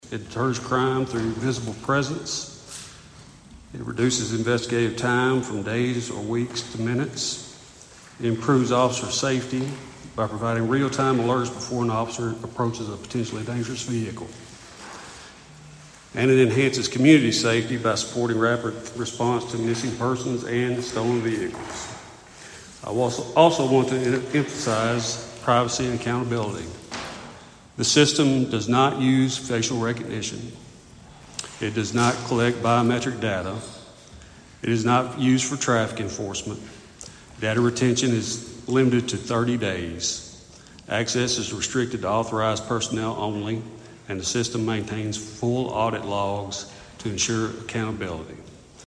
At Monday night’s meeting, the Princeton City Council approved applying for a grant to install automated license plate reader cameras at key city entry points.